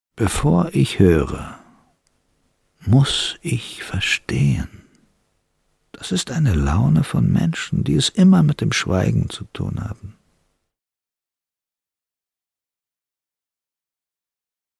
Werbung Motoo